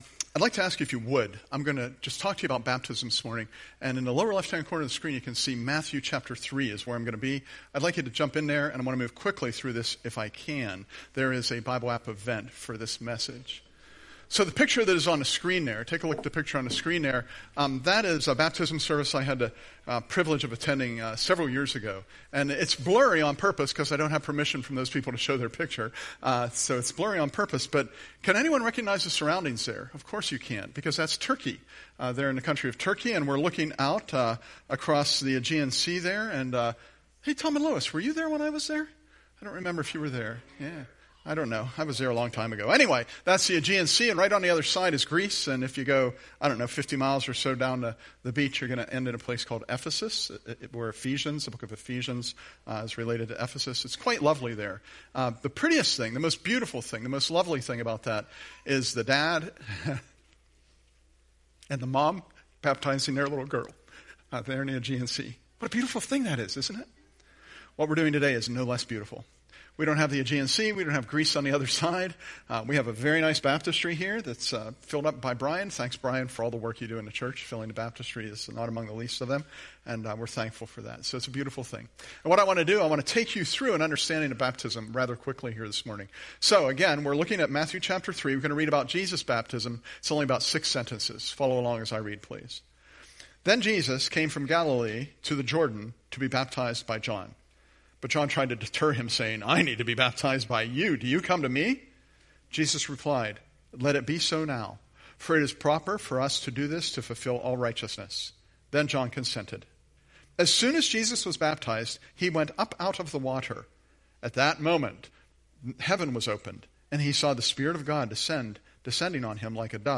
The Meaning of Baptism – Curwensville Alliance Church Podcasts